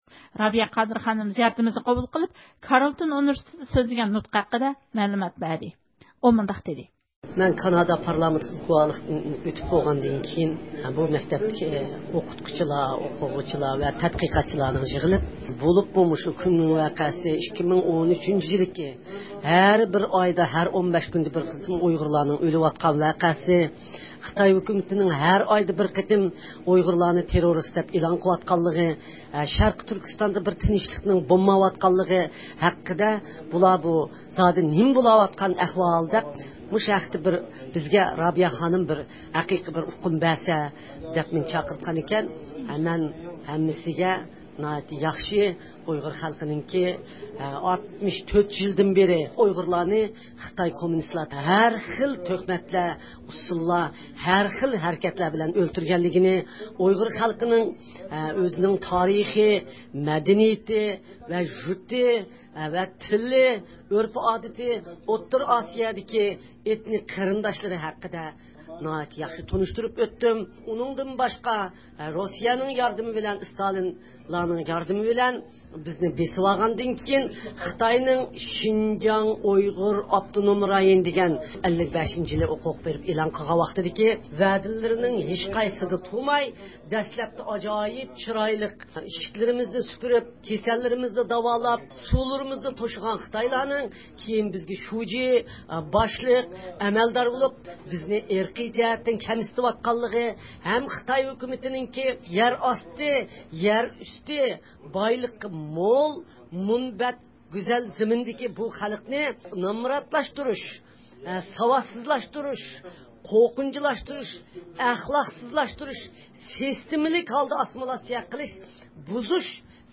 رابىيە قادىر خانىم ئوتتاۋا كارېلتون ئۇنىۋىرسىتېتىدا نۇتۇق سۆزلىدى – ئۇيغۇر مىللى ھەركىتى
رابىيە قادىر خانىمنىڭ نۇتقى بۇ ئۇنىۋىرسىتېتتا ئوقۇۋاتقان ئوقۇغۇچىلارنىڭ ئىنتايىن قىزىقىشى ۋە ھېسداشلىقىنى قوزغىغان بولۇپ، ئۇلار ئۇيغۇرلار ھەققىدە رابىيە خانىمدىن كۆپلەپ سوئاللار سورىغان.